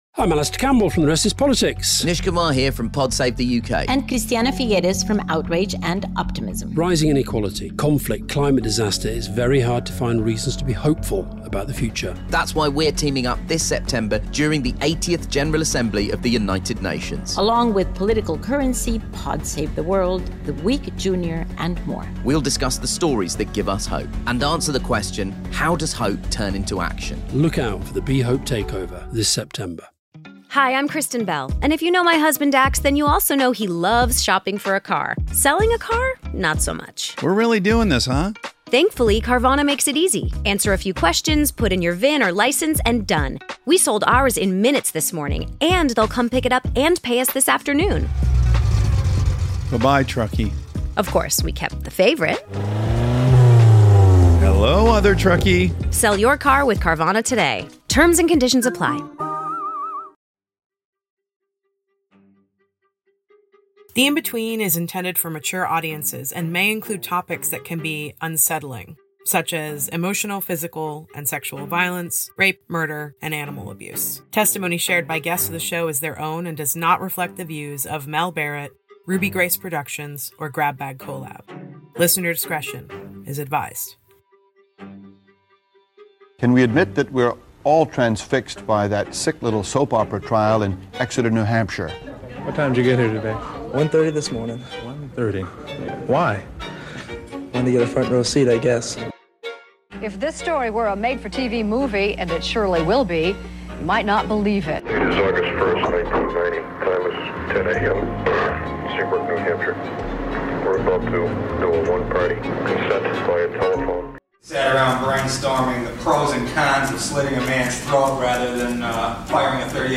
This is the only episode in the series that is comprised of an interview with a source.